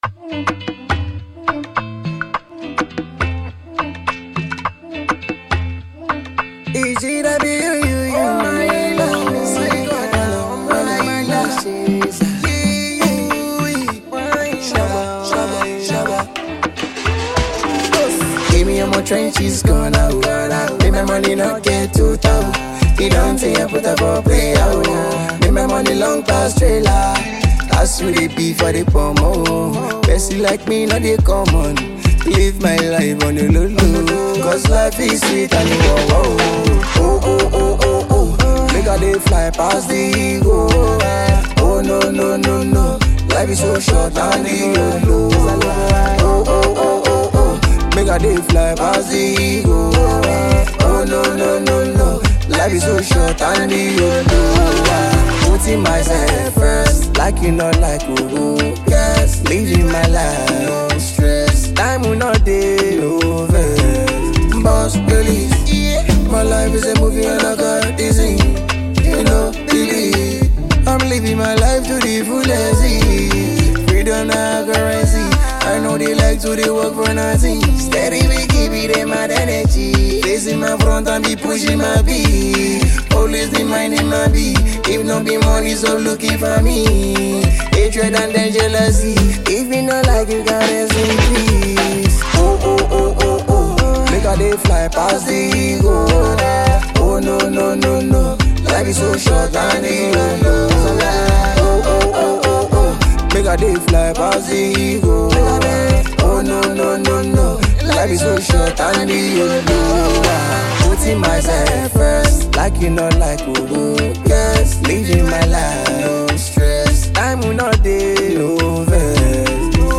With his soulful vocals and undeniable talent
infectious hooks